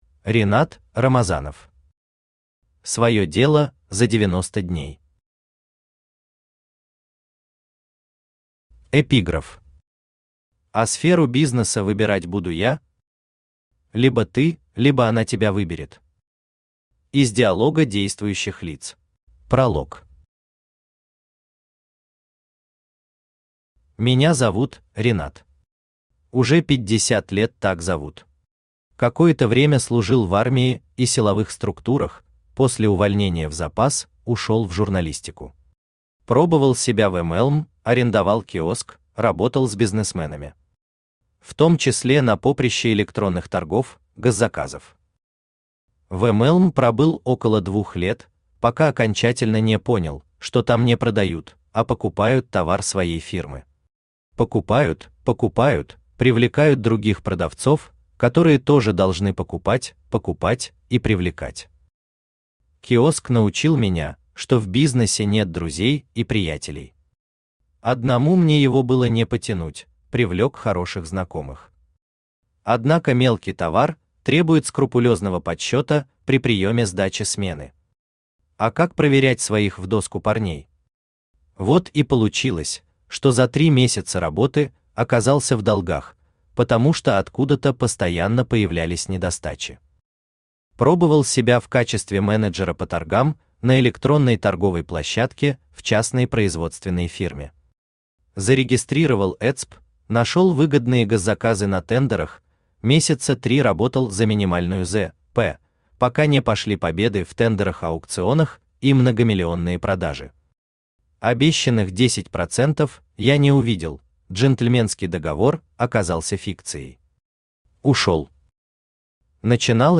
Аудиокнига Своё Дело за 90 дней | Библиотека аудиокниг
Aудиокнига Своё Дело за 90 дней Автор Ринат Рамазанов Читает аудиокнигу Авточтец ЛитРес.